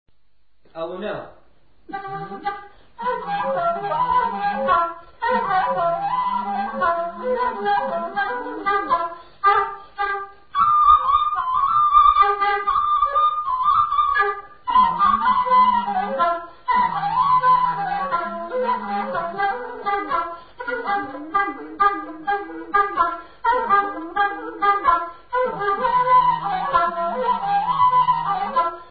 музикална класификация Инструментал
тематика Хороводна (инструментал)
размер Две четвърти
фактура Едногласна
начин на изпълнение Солово изпълнение на дудук
фолклорна област Средна Северна България
начин на записване Магнетофонна лента